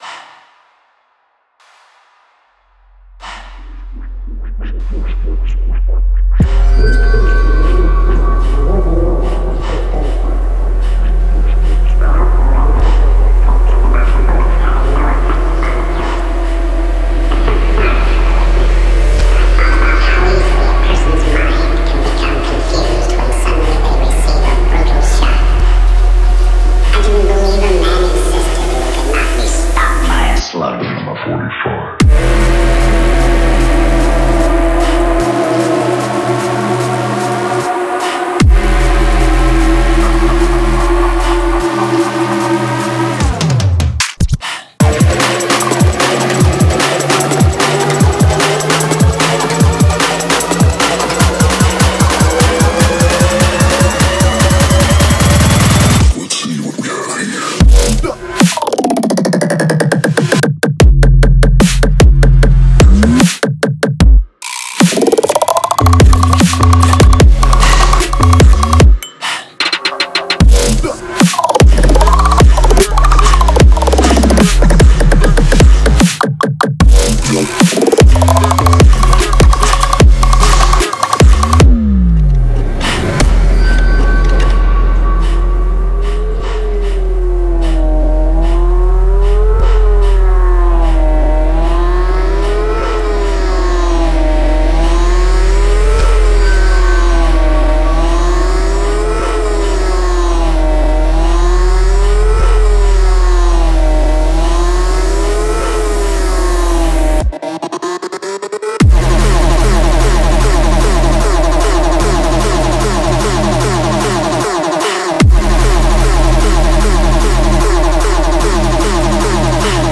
Жанр:load / Клубные новинки